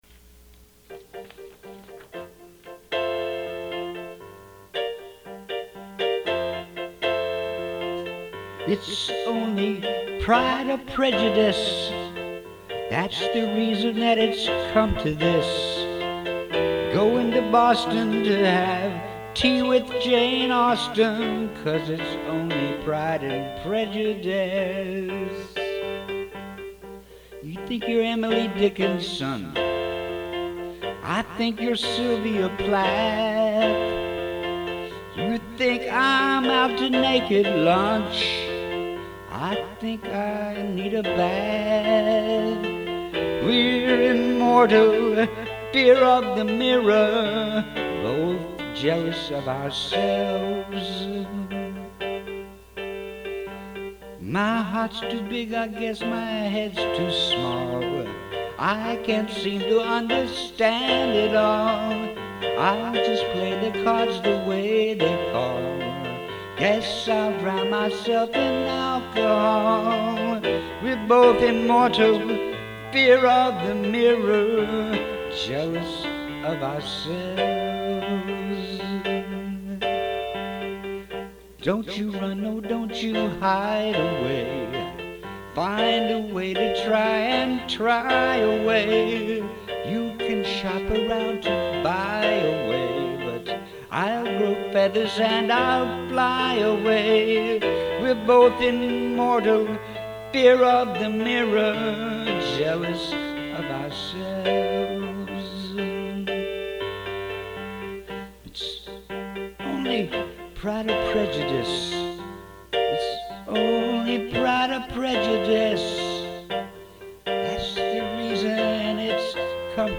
The music turned out lovely! I like the tune. Good job. It doesn't have a bridge, tho. ;) Not a critique, just an observation.